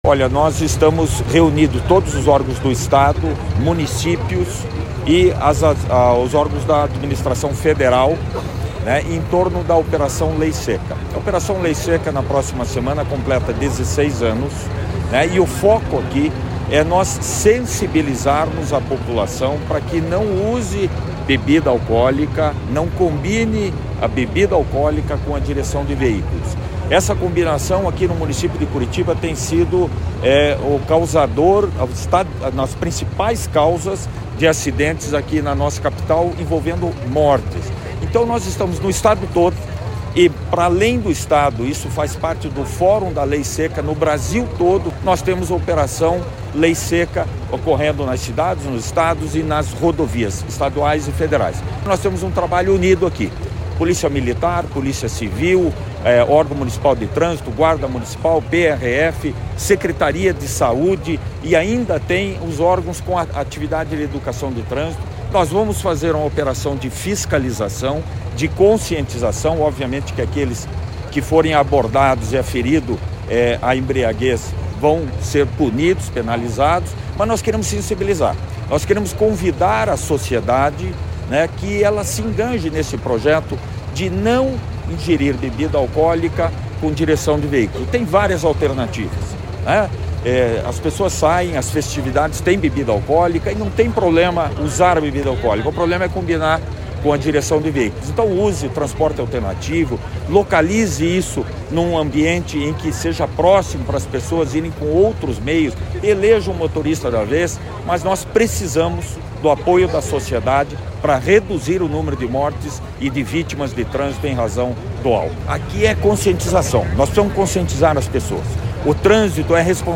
Sonora do diretor-presidente do Detran-PR, Adriano Furtado, sobre a Operação Lei Seca